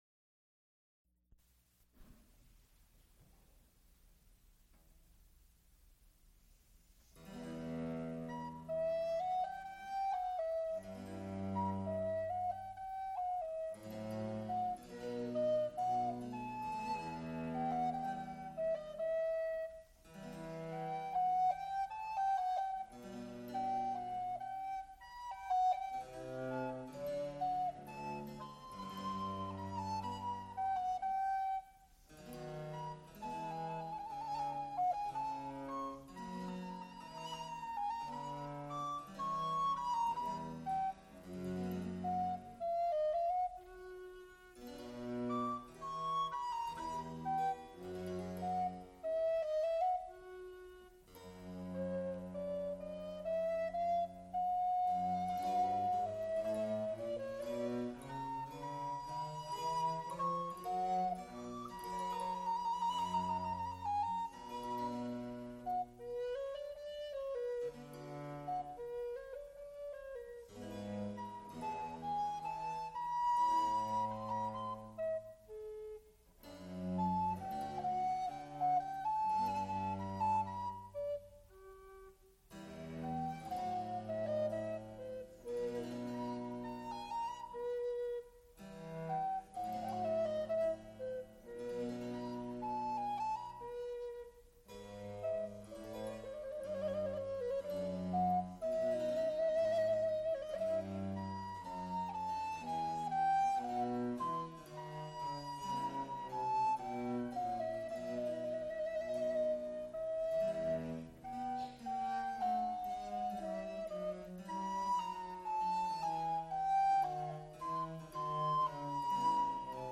for recorder and continuo
Recorded live February 16, 1978, Frick Fine Arts Auditorium, University of Pittsburgh.
Extent 3 audiotape reels : analog, quarter track, 7 1/2 ips ; 7 in.
Sacred songs (High voice) with continuo